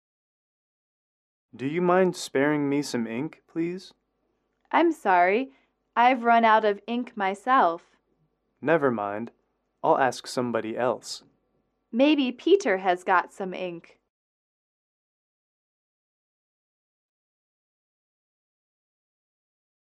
英语口语情景短对话17-4：借墨水（MP3）